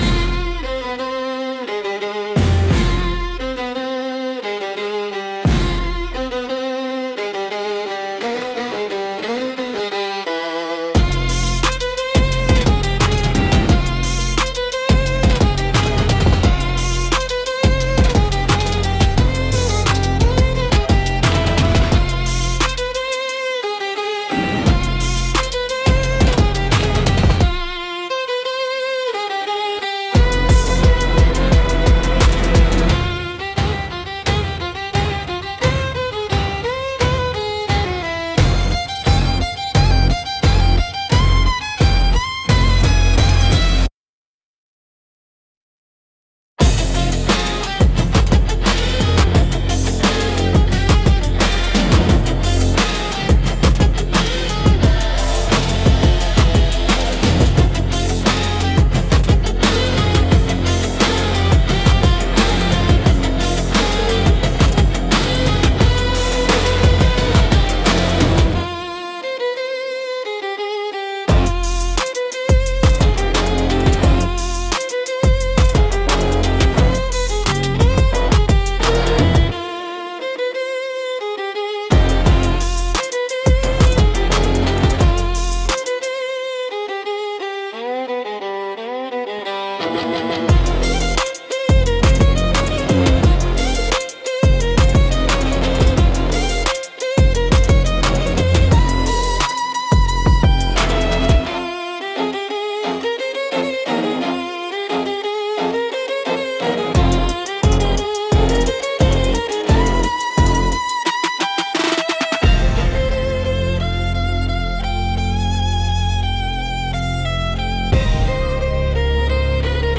Instrumental - Moonlight Dares Me